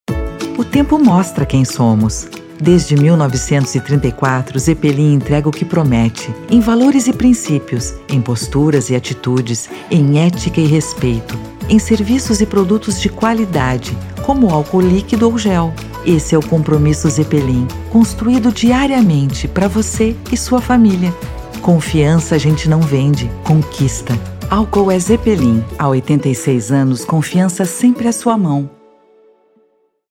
Feminino
Voz Padrão - Grave 00:30